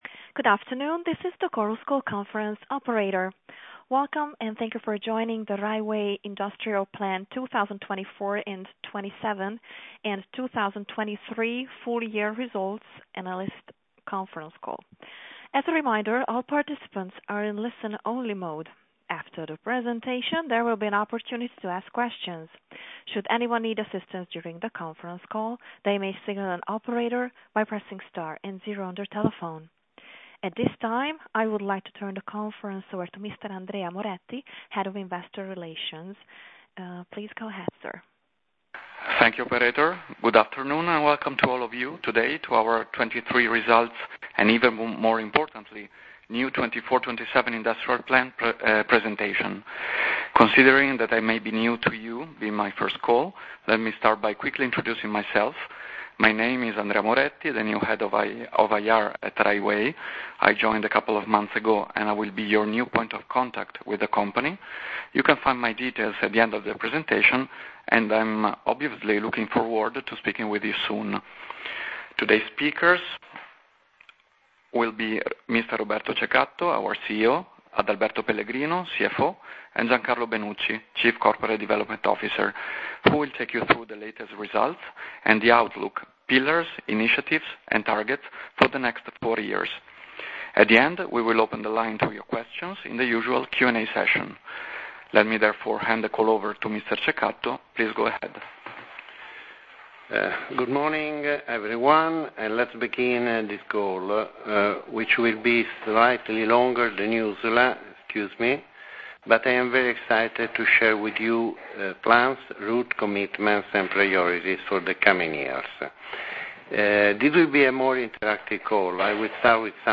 Audio mp3 Conference call Risultati Piano Industriale 2024-27 e risultati 2023FY